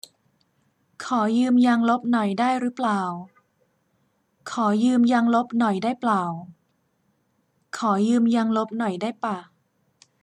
9. รึเปล่า [réu-plào] > เปล่า [plào] > ปะ [pà]